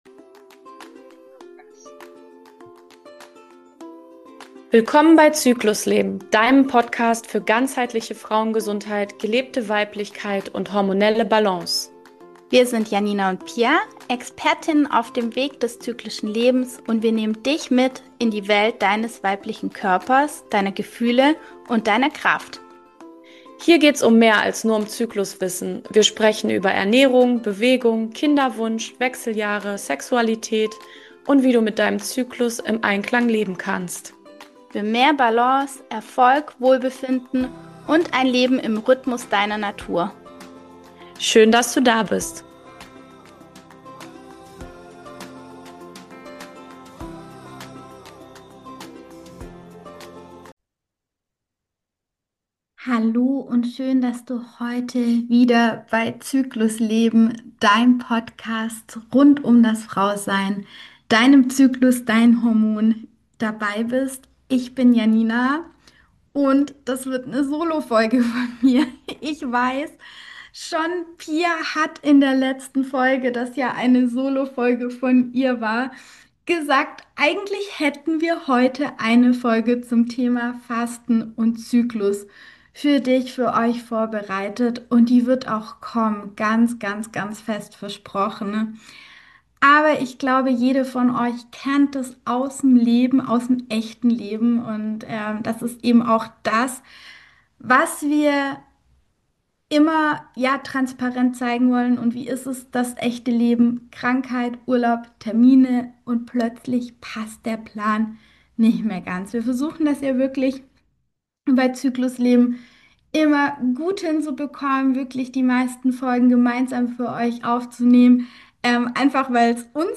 Krankheit und Urlaub haben unseren Aufnahmeplan etwas durcheinandergebracht, deshalb gibt es heute eine spontane Solo-Folge...